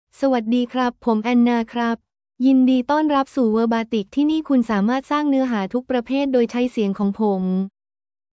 Anna — Female Thai AI voice
Anna is a female AI voice for Thai (Thailand).
Voice sample
Female
Anna delivers clear pronunciation with authentic Thailand Thai intonation, making your content sound professionally produced.